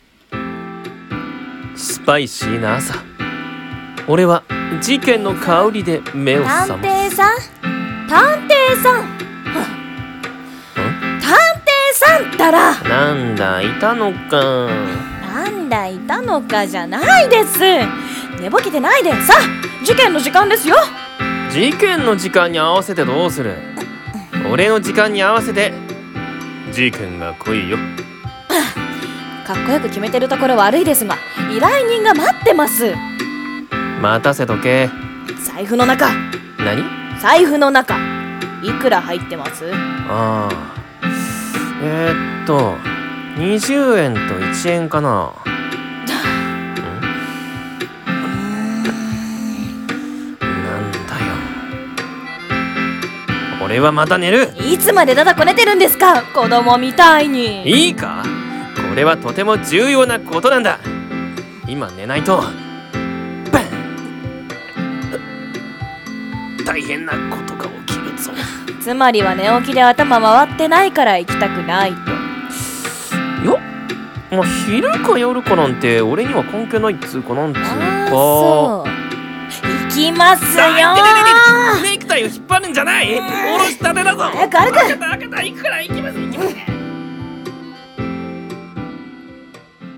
声劇台本『ひねくれ探偵と助手？の朝』